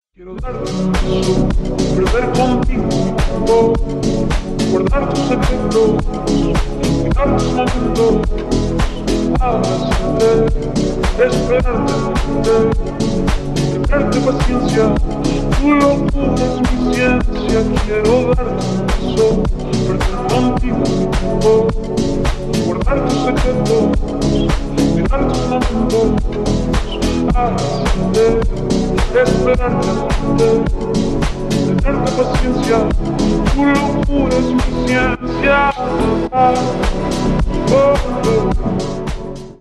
• Качество: 320, Stereo
deep house
атмосферные
Атмосферный ремикс